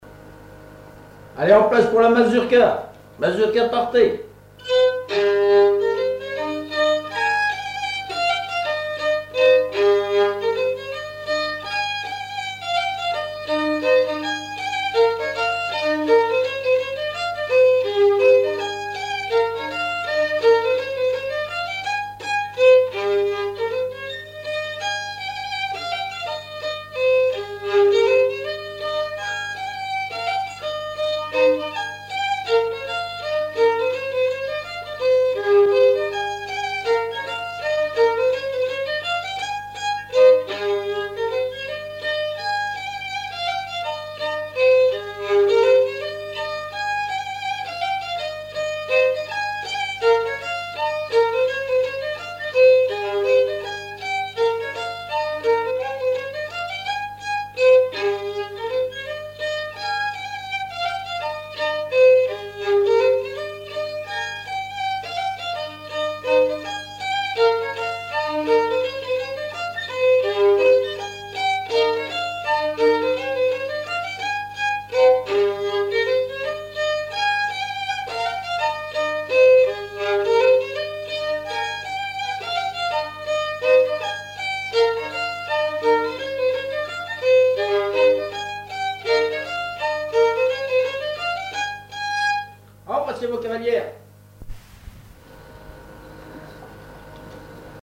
danse : mazurka
Auto-enregistrement
Pièce musicale inédite